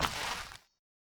hit4.ogg